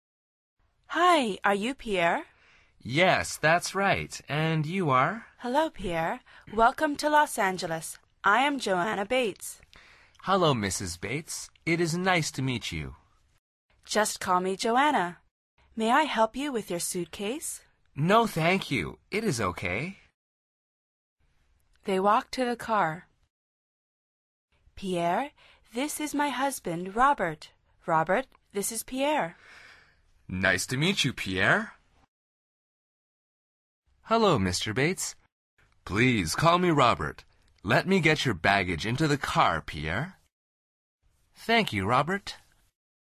Curso Básico de Conversación en Inglés
Al final repite el diálogo en voz alta tratando de imitar la entonación de los locutores.